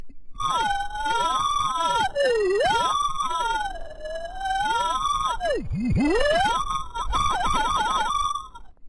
小型机器人P45累了
描述：机器人小星球大战r2d2累了低电池耗尽的科幻合成激光空间外星人小说科学 环境foley录音和实验声音设计。
标签： 小说 激光 小型 合成器 精疲力竭 外星人 战争 R2D2机器人 太空 科学 电池 累了 科幻
声道立体声